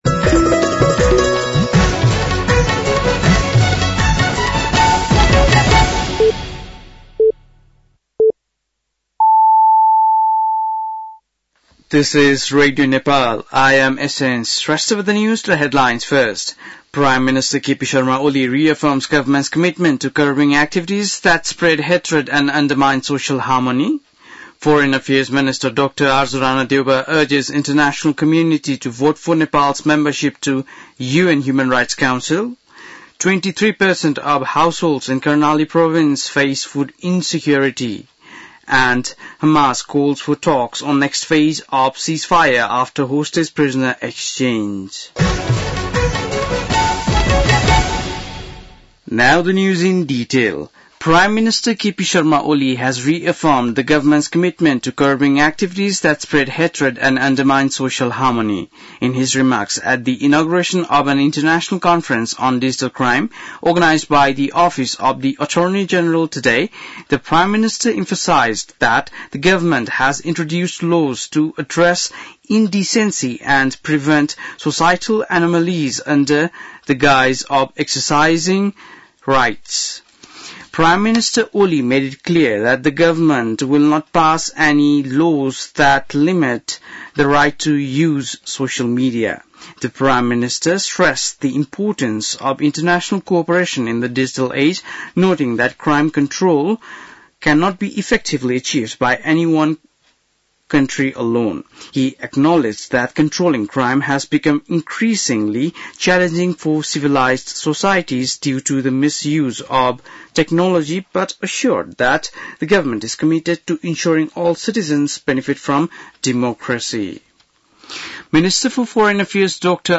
बेलुकी ८ बजेको अङ्ग्रेजी समाचार : १६ फागुन , २०८१
8-PM-English-NEWS-11-15.mp3